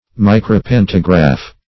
Search Result for " micropantograph" : The Collaborative International Dictionary of English v.0.48: Micropantograph \Mi`cro*pan"to*graph\, n. [Micro- + pantograph.] A kind of pantograph which produces copies microscopically minute.